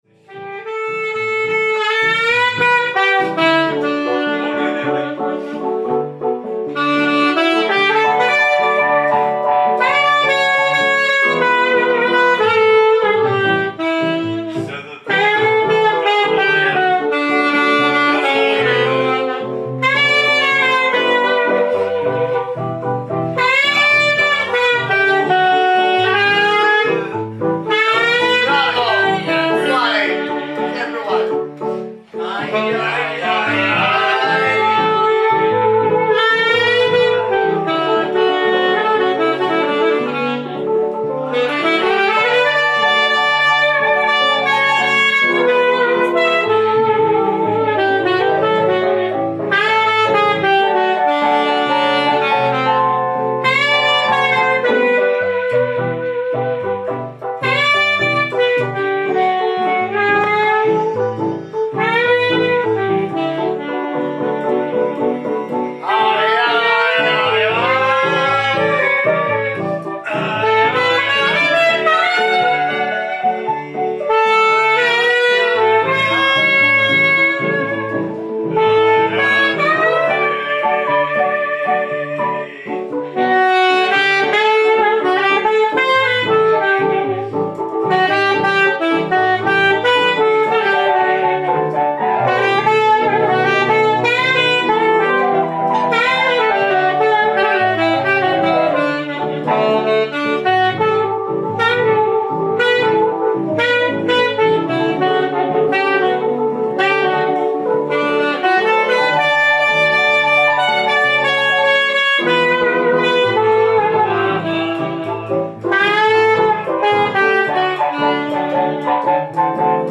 This evening there were just two musicians playing... and playing is the precise word to use as they were both toying with the music and having fun
keyboards
sax